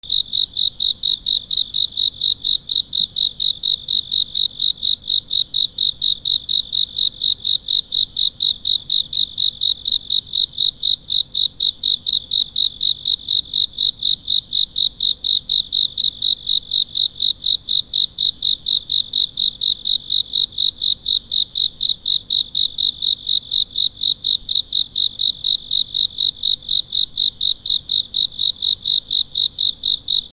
/ B｜環境音(自然) / B-30 ｜虫の鳴き声 / 虫10_虫の鳴き声10_街、近郊(少なめ)
1 虫の鳴き声
『リーリー』